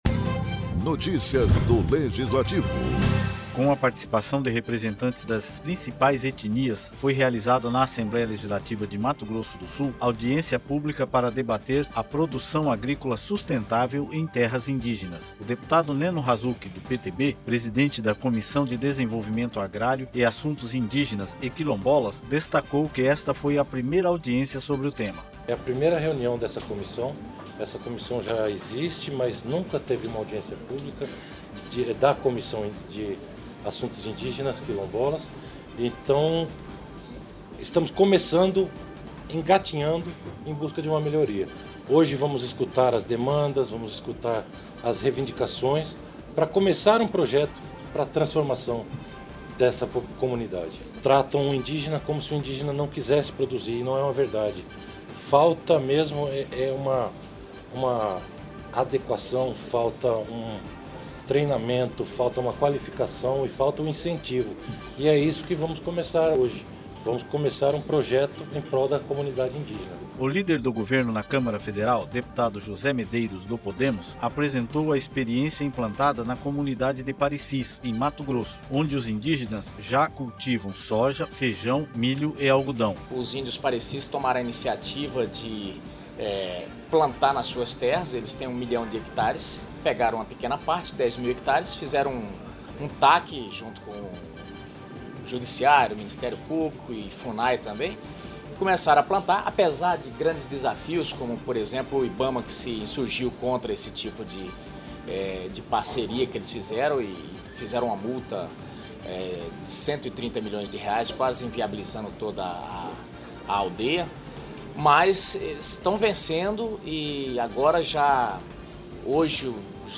Para debater produção agrícola sustentável em terras indígenas a Assembleia Legislativa de Mato Grosso do Sul (ALEMS) realizou, nesta quinta-feira (26), audiência pública Desenvolvimento Agrário em Terras Indígenas.
Locução e Produção